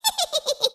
driada_risa.wav